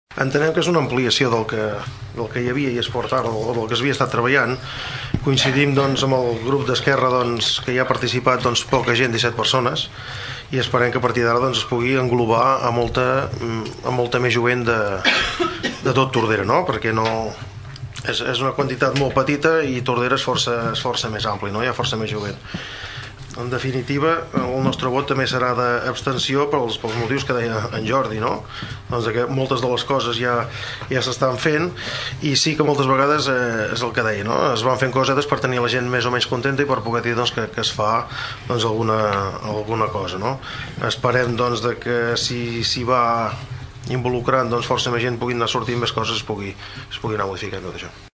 El regidor del PSC, Josep Romaguera, justificava el vot d’abstenció considerant que el nombre de joves que hi ha participat és molt reduït, tenint en compte la població jove del municipi.